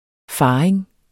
Udtale [ ˈfɑːeŋ ]